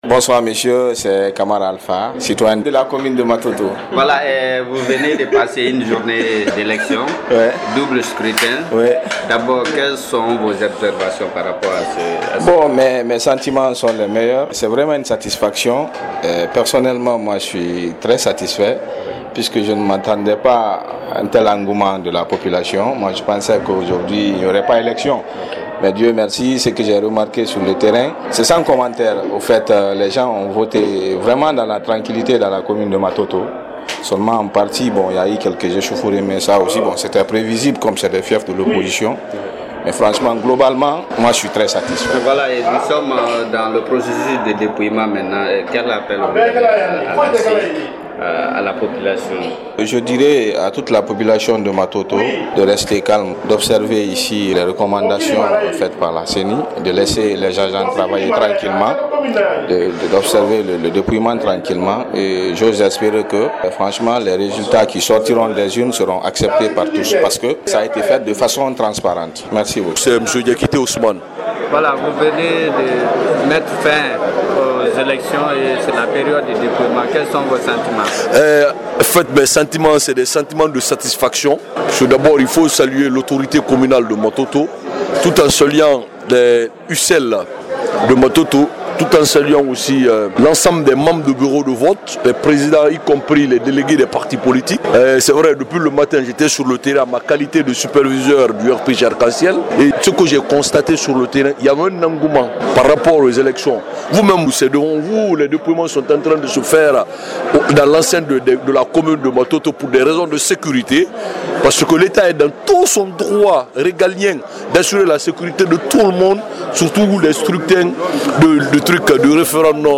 Les guinéens se sont rendus aux urnes ce dimanche 22 mars 2020  pour choisir leurs députés à l’assemblée nationale et pour voter une nouvelle constitution. Malgré des incidents enregistrés par endroits, à Matoto ,d’une manière générale les scrutins se sont déroulés bien et   les opérations de dépouillement ont été effectuées sans incidents majeurs à la mairie .Nous avons tendus notre micro sur place à certains acteurs impliqués dans le processus électoral.